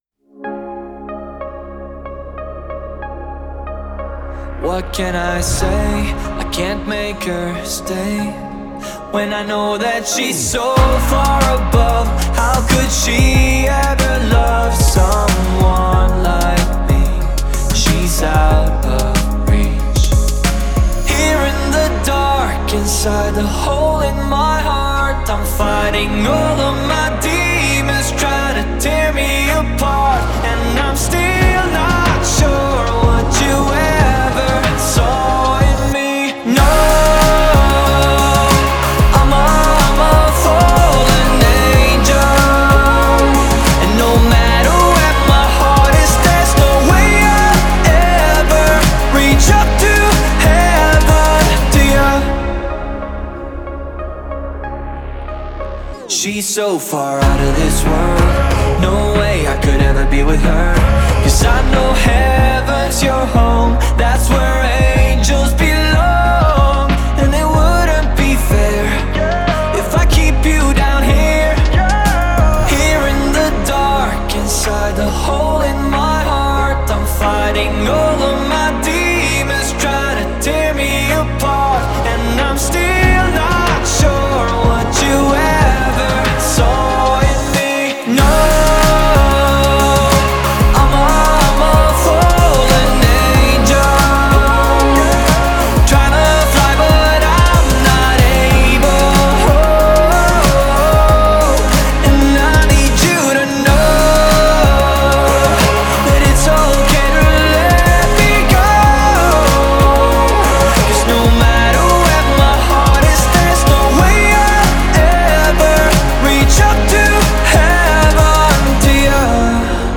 это эмоциональная баллада в жанре поп
Настроение песни – меланхоличное, но с нотками оптимизма.